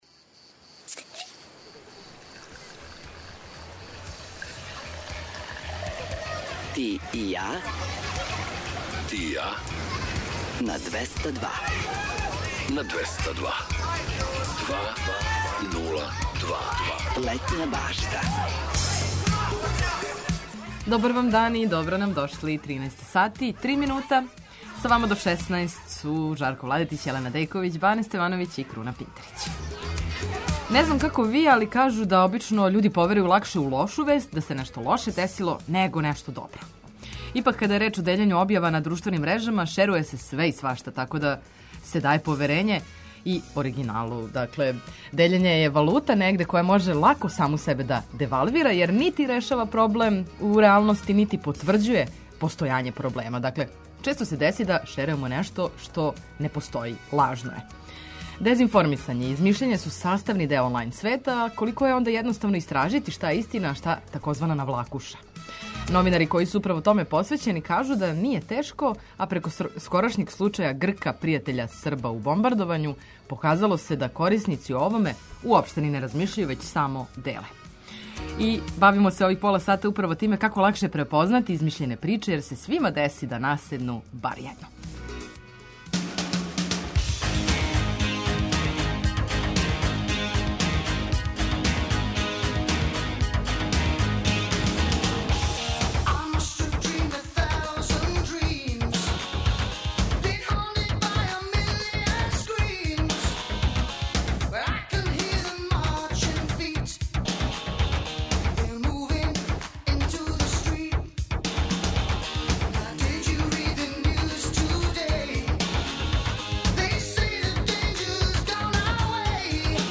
У наставку емисије очекује вас више музике, приче о песмама, важним албумима, рођенданима музичара, а завирићемо и у највеће хитове светских топ листа.
Предлажемо вам предстојеће догађаје широм Србије, свирке и концерте, пратимо сервисне информације важне за организовање дана, а наш репортер је на градским улицама, са актуелним причама.